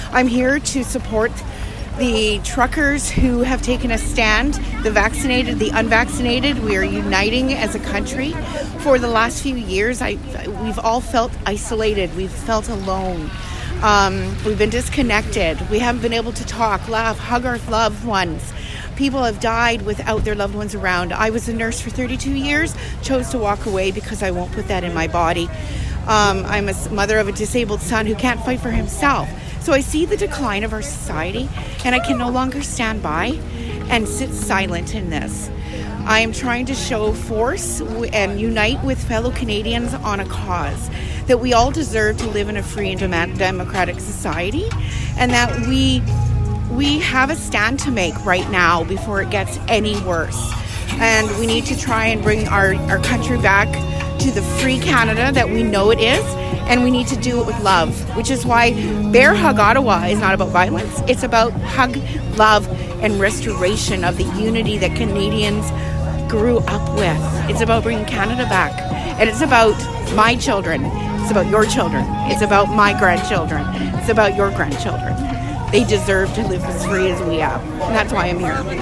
Des camionneurs venant de partout en Atlantique ont pris la route, jeudi, pour dénoncer les mandats gouvernementaux. Des milliers de manifestants se sont rassemblés à la halte routière de Lincoln en après-midi.